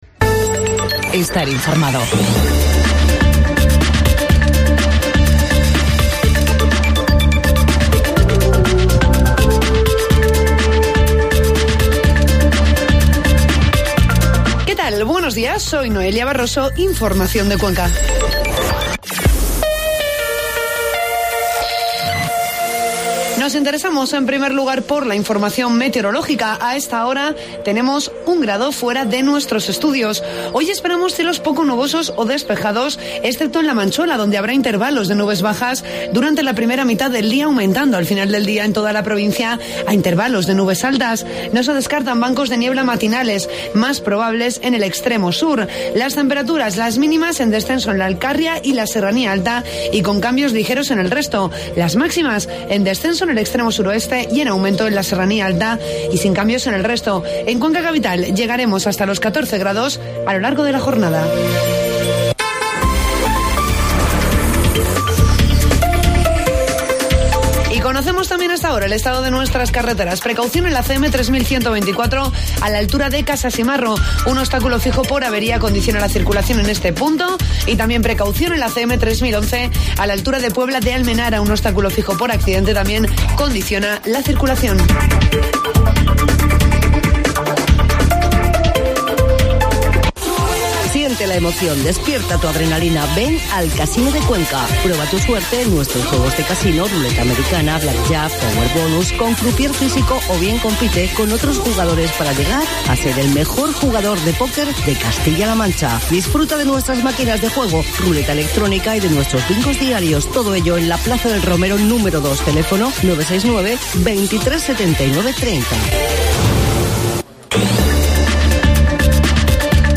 Informativo matinal COPE Cuenca 18 de enero